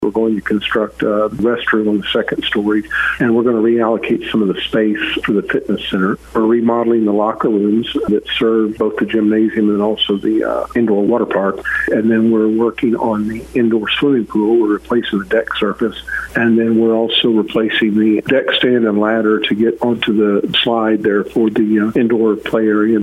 Farmington City Administrator, Greg Beavers, explains work on the Civic Center will begin in about a month.